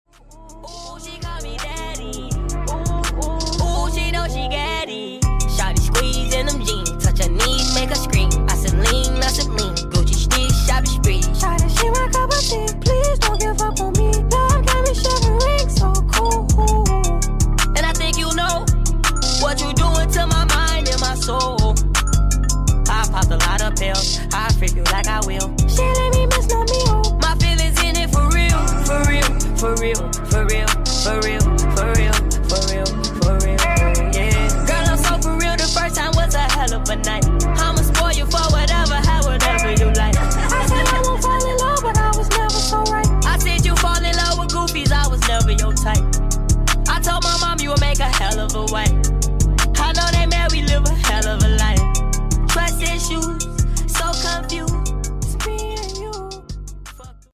[sped up]